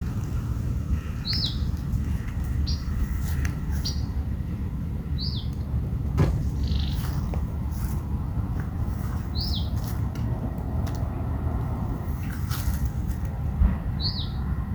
Boyerito (Icterus pyrrhopterus)
Nombre en inglés: Variable Oriole
Condición: Silvestre
Certeza: Observada, Vocalización Grabada